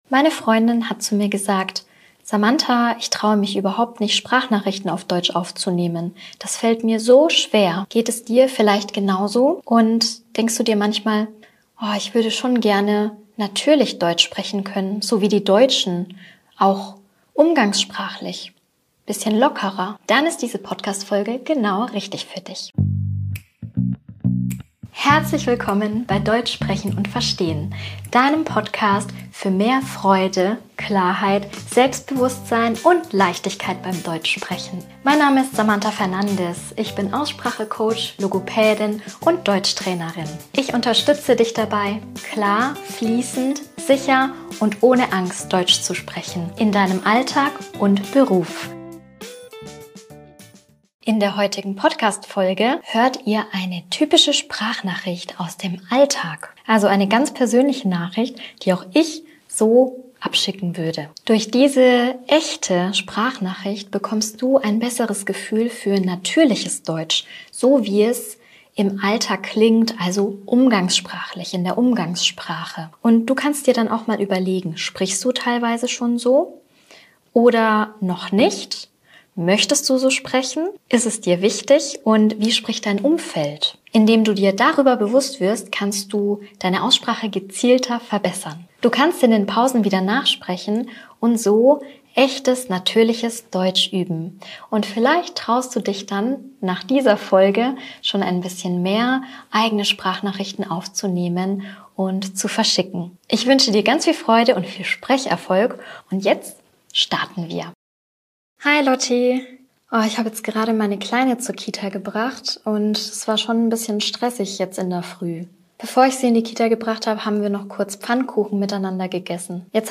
In dieser Folge hörst du eine echte Sprachnachricht aus dem Alltag und übst typische Sätze, wie sie im gesprochenen Deutsch wirklich klingen. Hör zu und sprich in den Pausen laut nach.